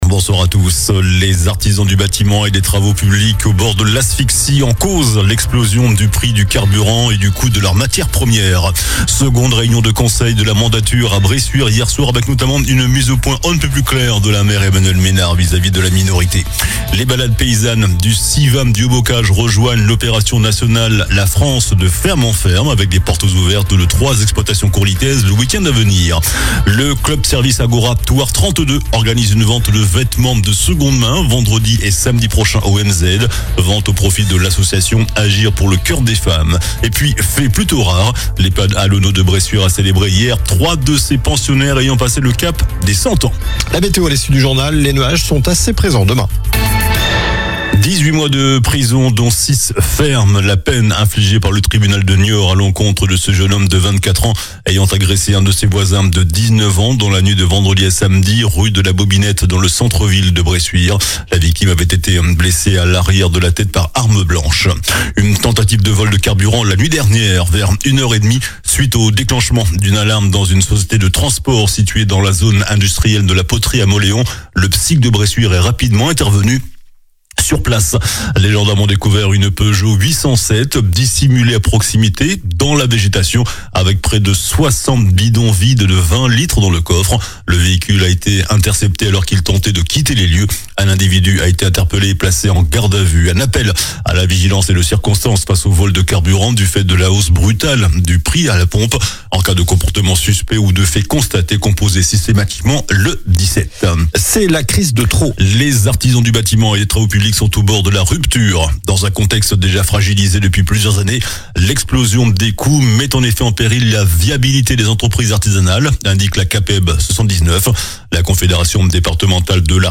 JOURNAL DU MARDI 21 AVRIL ( SOIR )